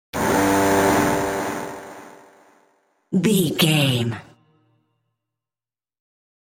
In-crescendo
Thriller
Aeolian/Minor
tension
ominous
eerie
synthesiser
Horror Synths